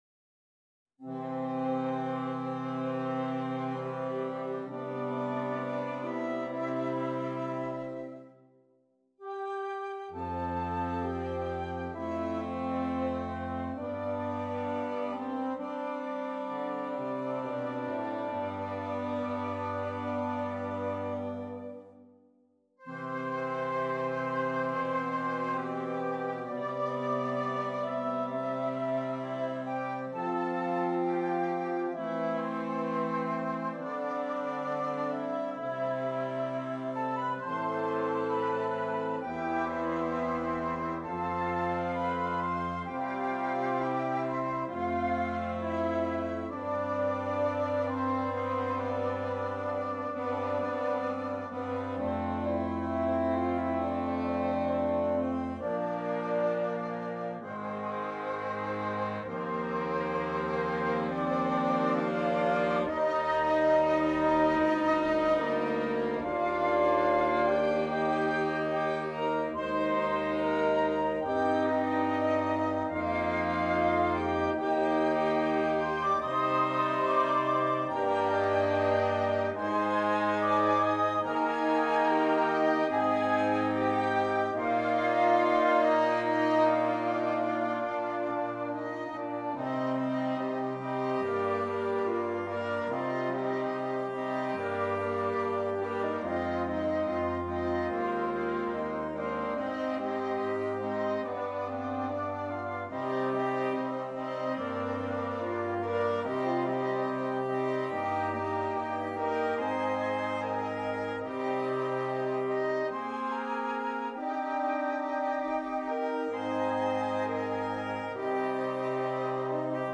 Interchangeable Woodwind Ensemble
Sounding very patriotic in nature